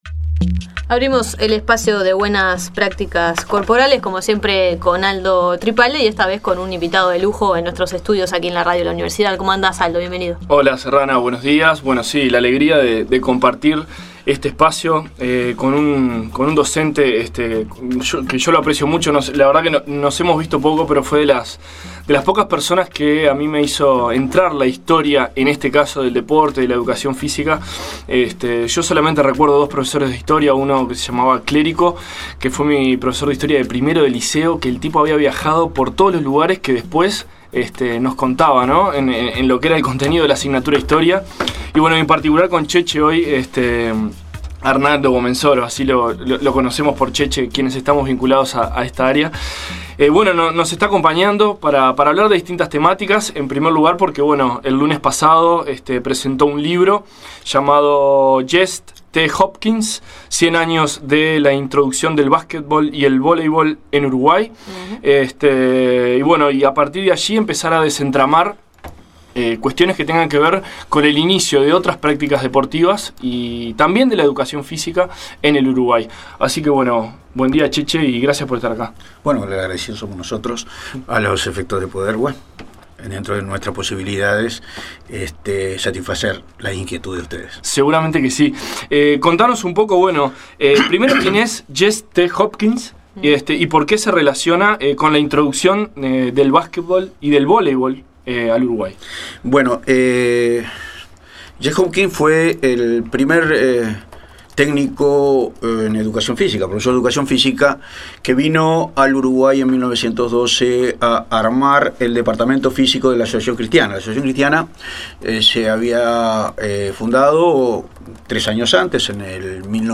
Audio: Viaje al pasado de los deportes en Uruguay. Entrevista